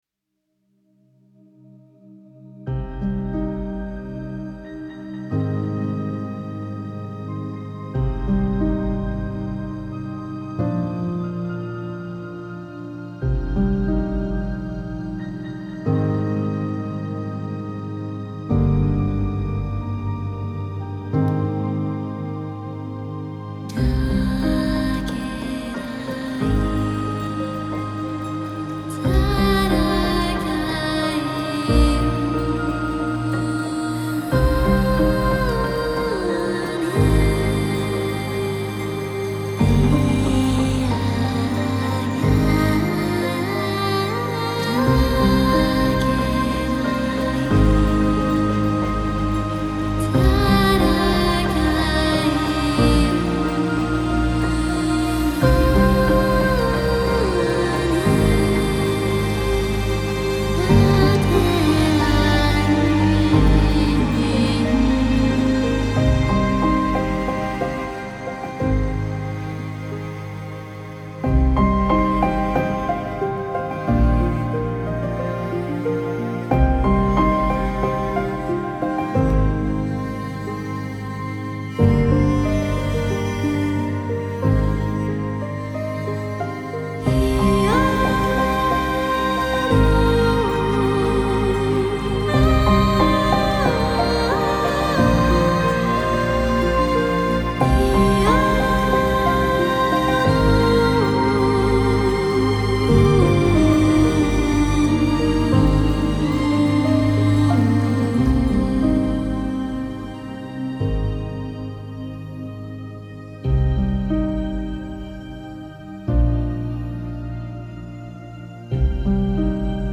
موسیقی بی کلام آرامش‌بخش
آرامش بخش , تخیلی و رویایی , موسیقی بی کلام